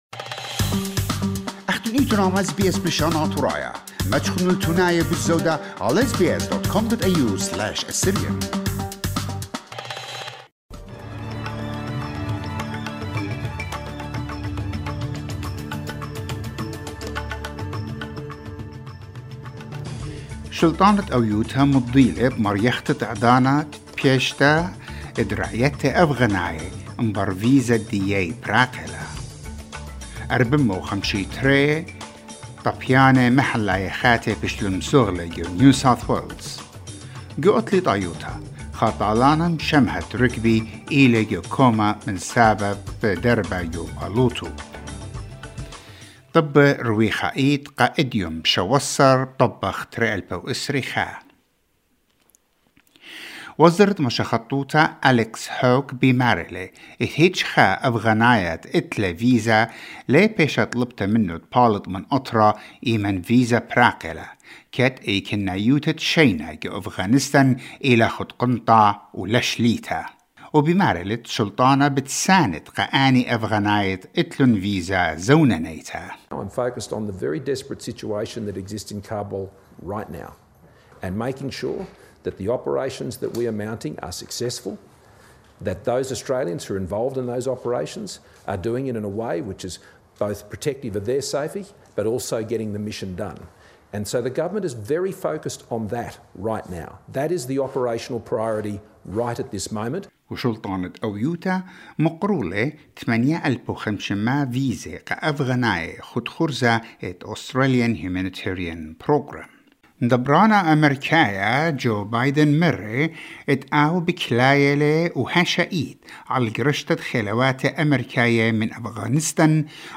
SBS NEWS in ASSYRIAN 17 AUGUST 2021